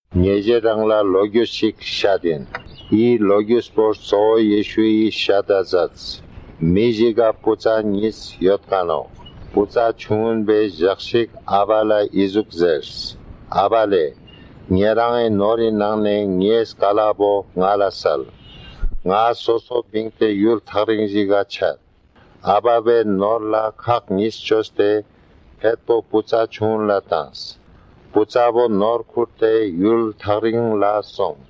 Otherwise it sounds like a Tibeto-Burman language, with all its initial palatal and velar nasals, simple syllable structure, etc. That all fits with being spoken in India.